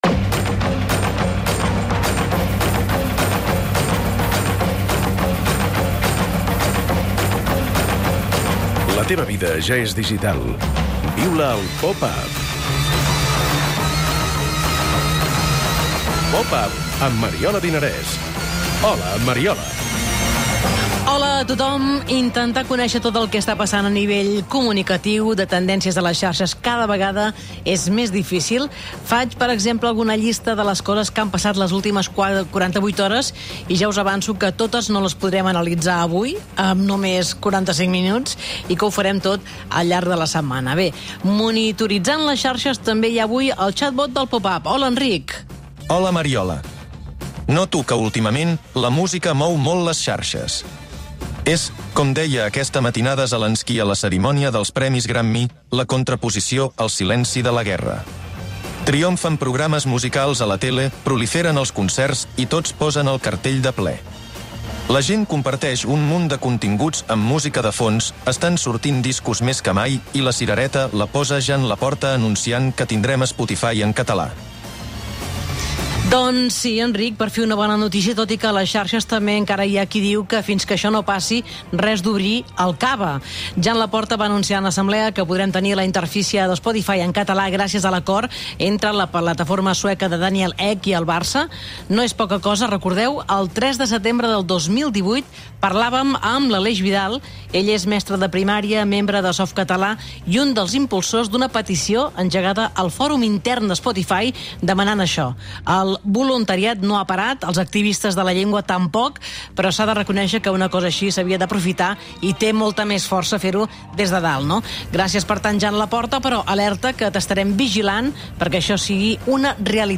I analitzem el fenomen de la sèrie d'HBO Max "Julia". I entrevista